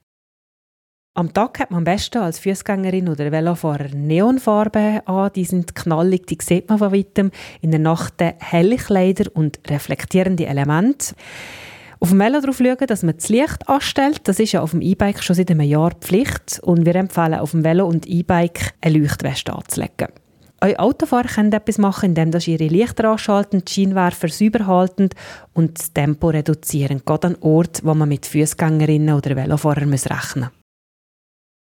Die BFU bietet als Service einige Aussagen der Medienmitteilung als O-Ton zum Download an.